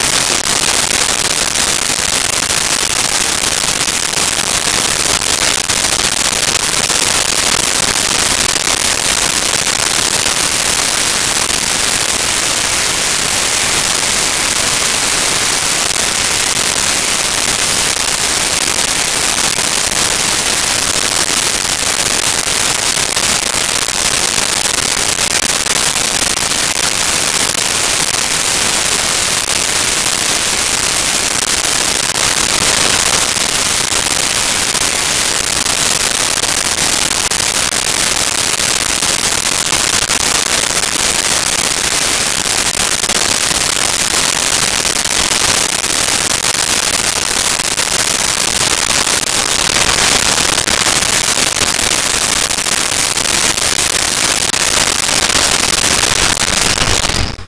Jungle Birds And Insects
Jungle Birds And Insects.wav